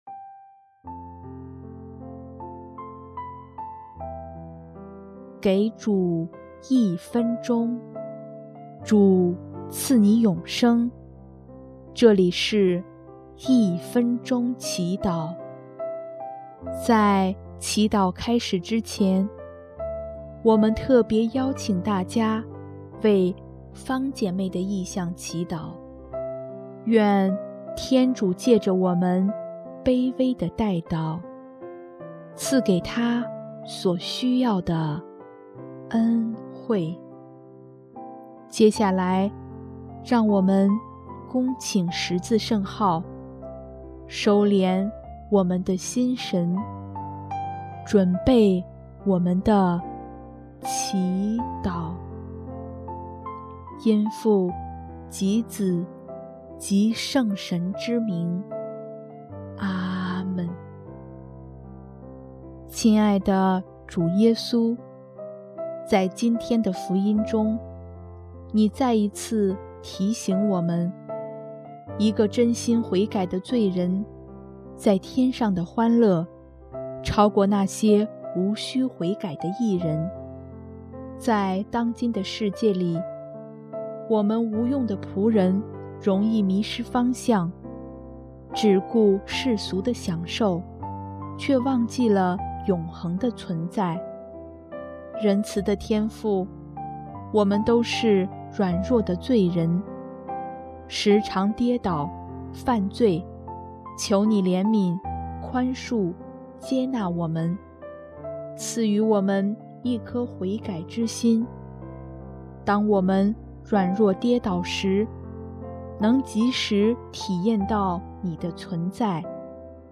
音乐：第一届华语圣歌大赛参赛歌曲